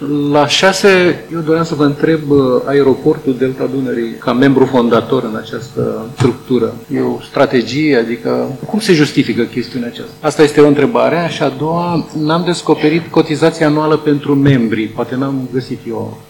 Vineri, 27 iunie, Consiliul Județean Tulcea a votat înființarea Organizației de Management al Destinației Turistice „Eco‑Delta”.
Discuțiile au fost deschise de consilierul PNL Nicolae Chichi, care a cerut detalii privind rolul Aeroportului Internațional Delta Dunării în acest demers, precum și despre modul de stabilire a cotizațiilor anuale: